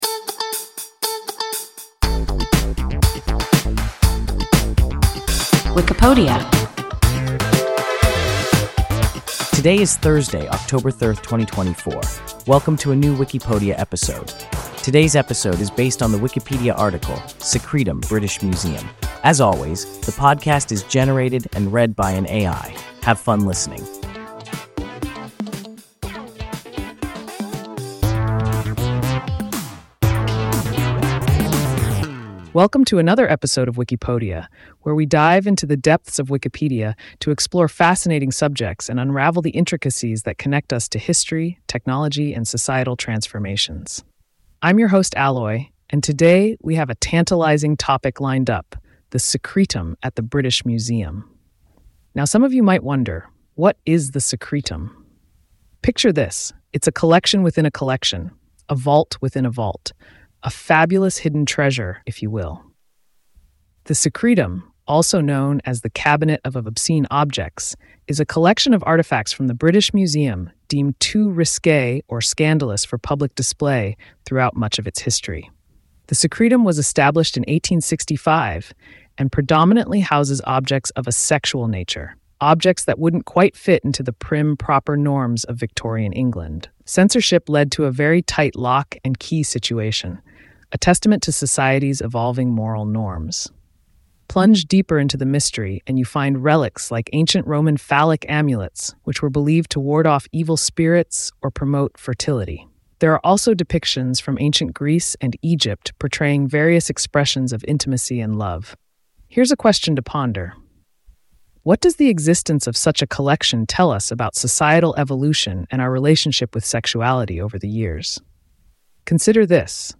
Secretum (British Museum) – WIKIPODIA – ein KI Podcast